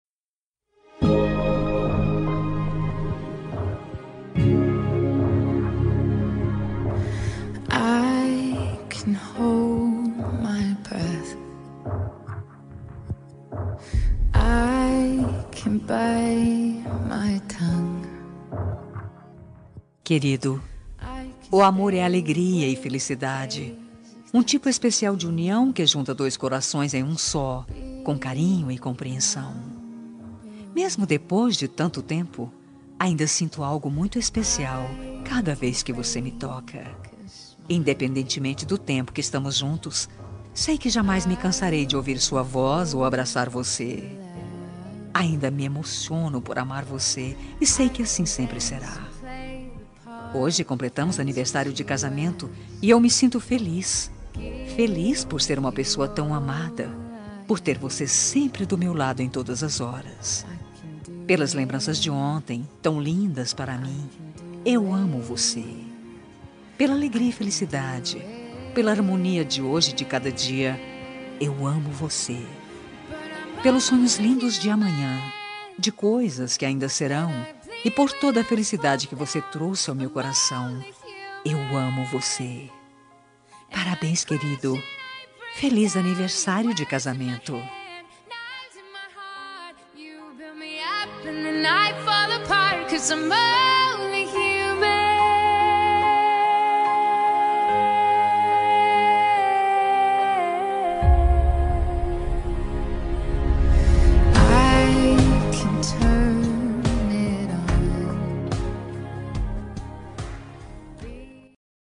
Aniversário de Casamento – Voz Feminina – Cód: 8108 – Linda.